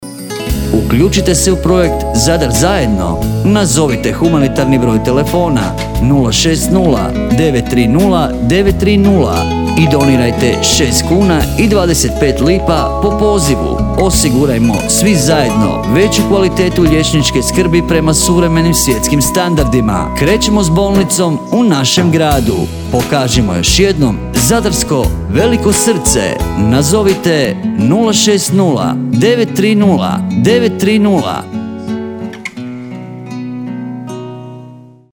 Zadar-zajedno-spot.mp3